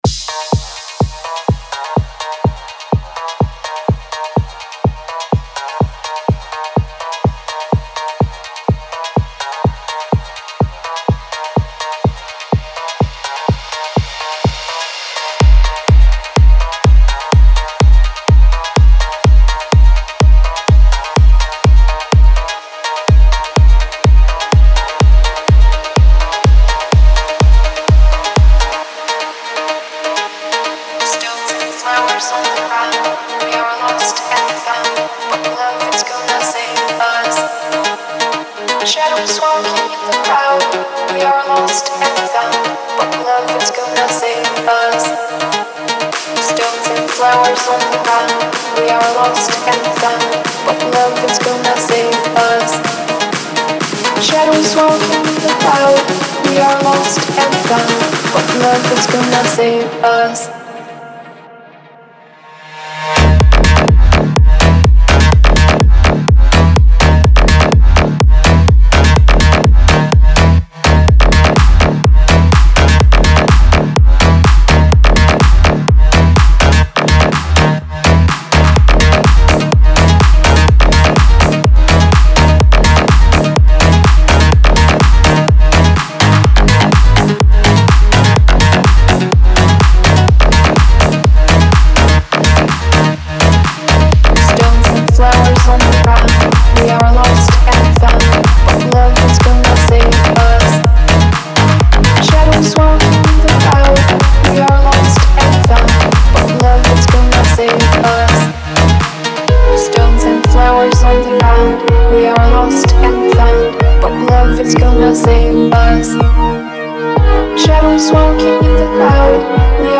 • Жанр: Dance, EDM